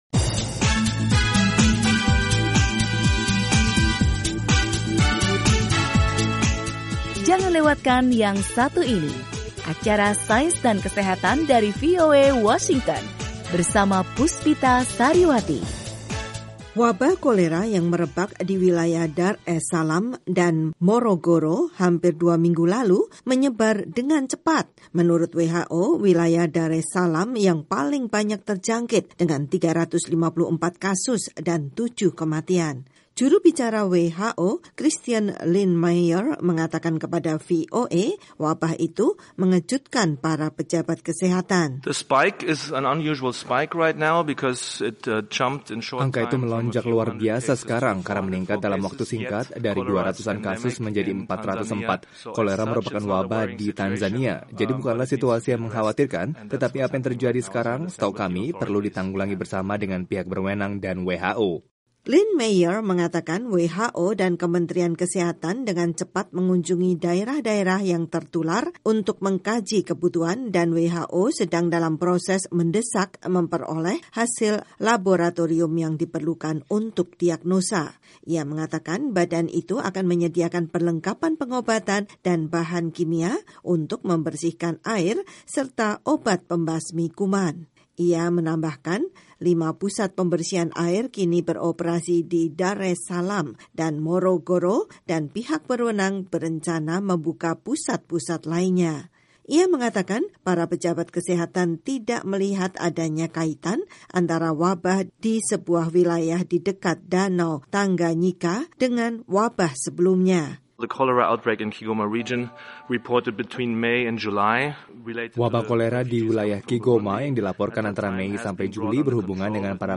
Organisasi Kesehatan Dunia, WHO dan Kementerian Kesehatan Tanzania meningkatkan upaya-upaya untuk menemukan sumber wabah kolera yang sejauh ini telah menewaskan delapan orang dan menjangkiti lebih dari 400 lainnya. Laporan selanjutnya